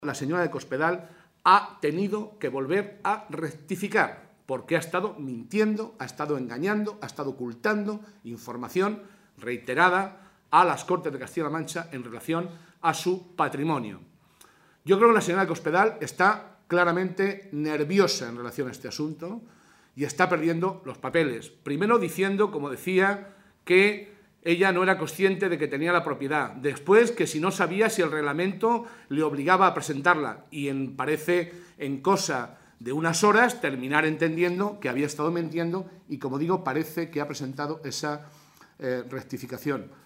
José Molina, portavoz del Grupo Parlamentario Socialista
Cortes de audio de la rueda de prensa